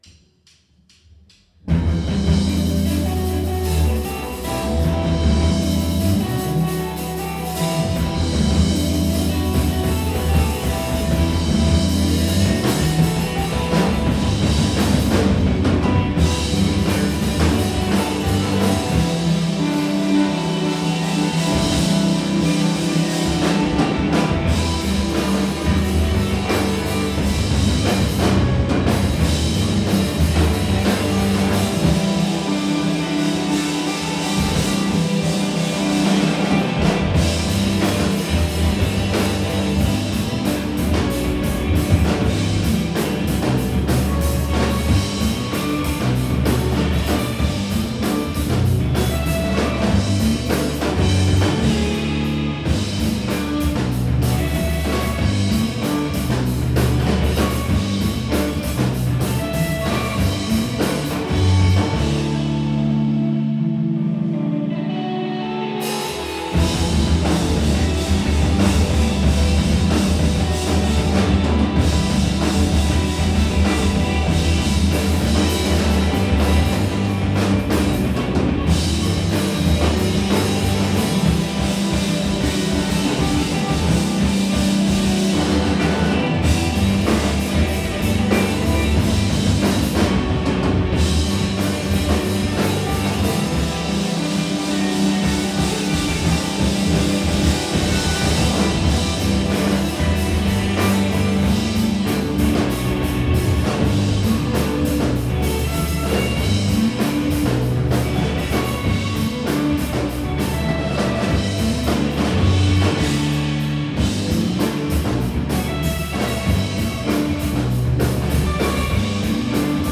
2007-04-28 Ground Zero – Bellevue, WA